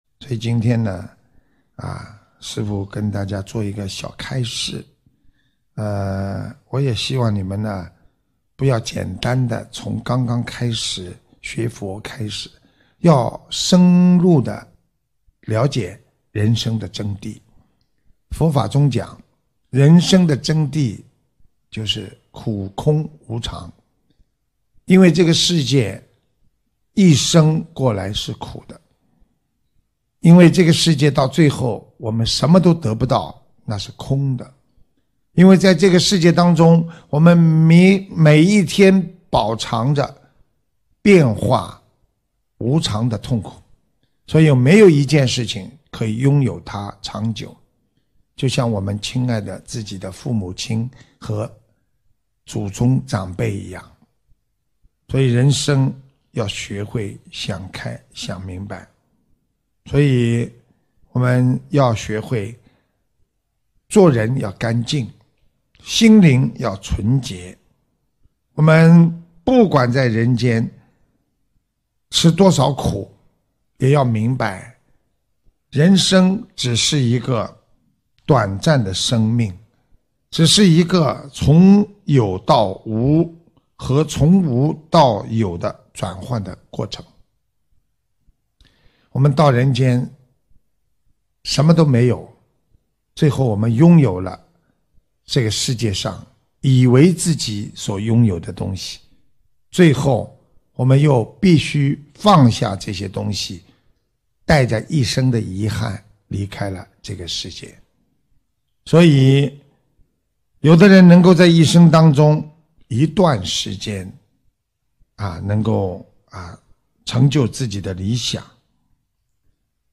视频:579_珍惜别人的感情，珍惜别人对你的爱，珍惜菩萨对你的慈悲，你本身就是拥有智慧 - 法会开示 百花齐放